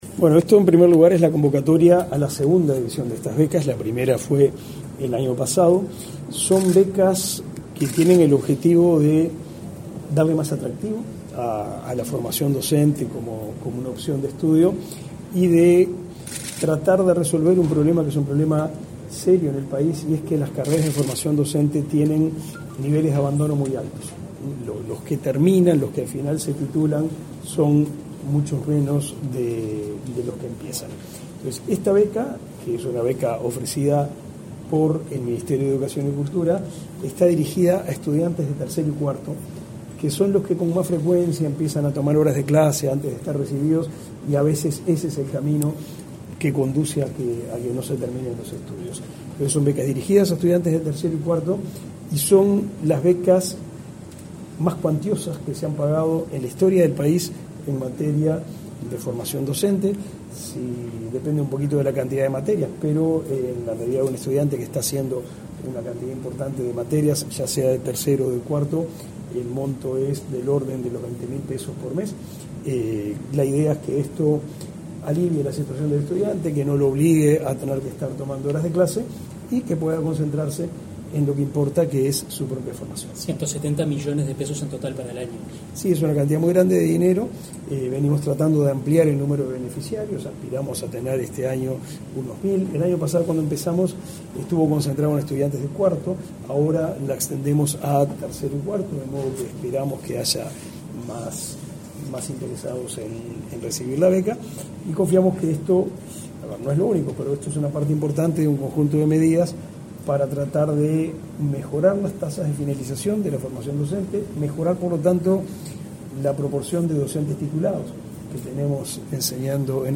Declaraciones a la prensa del titular del MEC, Pablo da Silveira
Declaraciones a la prensa del titular del MEC, Pablo da Silveira 23/01/2024 Compartir Facebook X Copiar enlace WhatsApp LinkedIn El Ministerio de Educación y Cultura (MEC) realizó, este 23 de enero, el lanzamiento de la beca Docente Acreditado para estudiantes de Formación Docente. Tras el evento, el titular de la cartera, Pablo da Silveira, realizó declaraciones a la prensa.